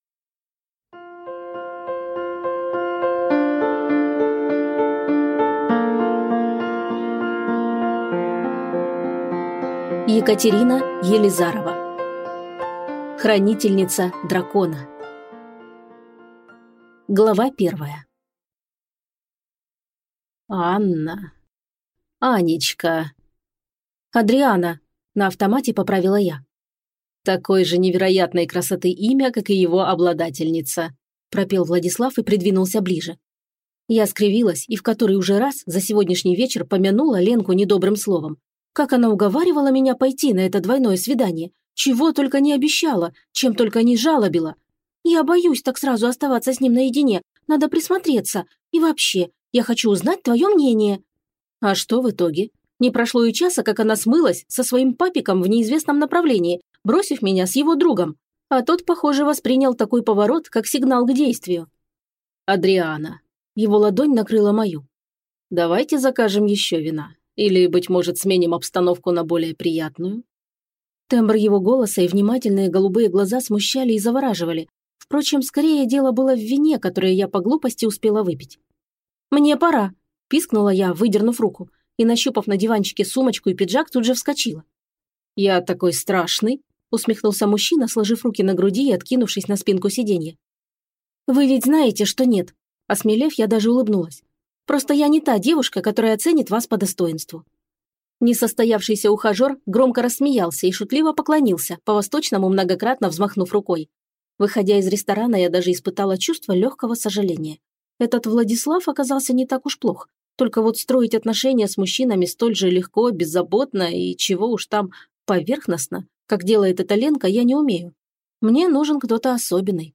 Аудиокнига Хранительница дракона | Библиотека аудиокниг
Прослушать и бесплатно скачать фрагмент аудиокниги